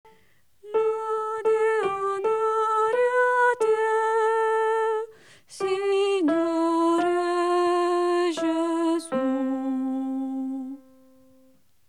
CONTRALTISOPRANI
CDV101-Lode-e-Onore-a-Te-Signore-Gesu-CONTRALTI-Acclamazione-2^-melodia.mp3